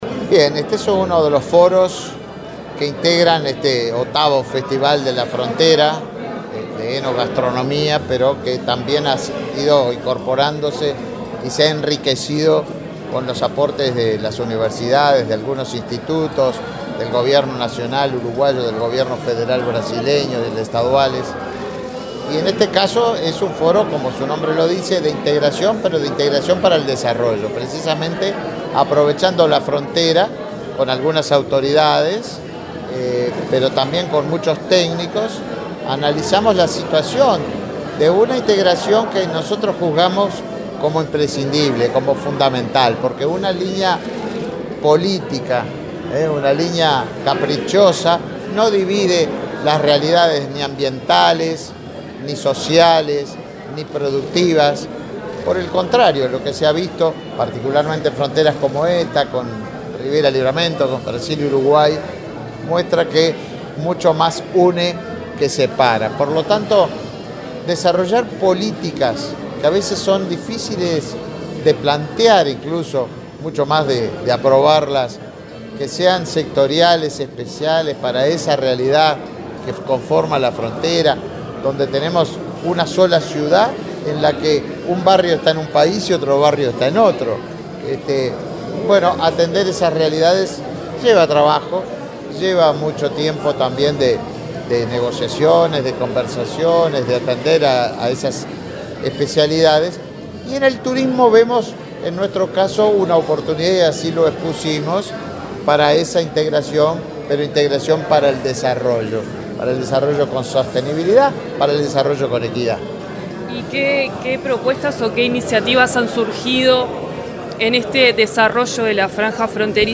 Entrevista al ministro de Turismo, Tabaré Viera
El ministro de Turismo, Tabaré Viera, dialogó con Comunicación Presidencial, el sábado 5 en Rivera, donde participó en el Foro Binacional de